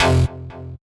Misc Synth stab 05.wav